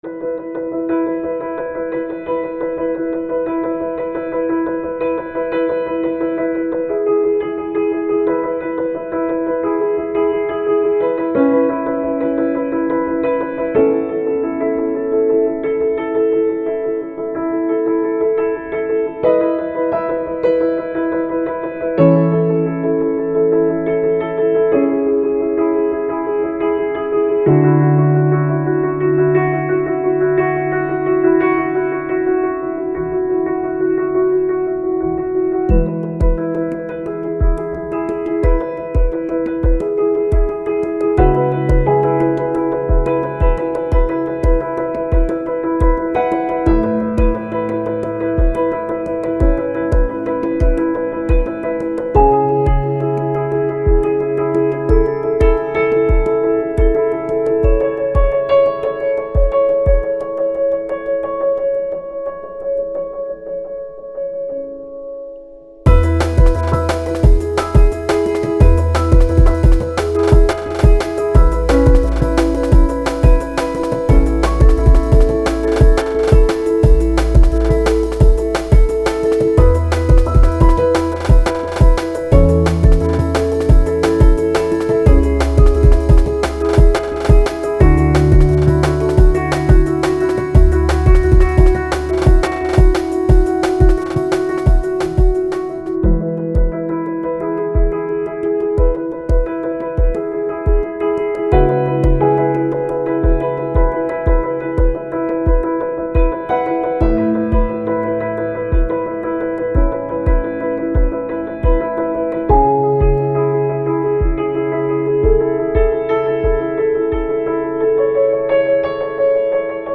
Для корпоративной музыки очень вкатил: Вложения New Corporate Trend.mp3 New Corporate Trend.mp3 2,7 MB · Просмотры: 312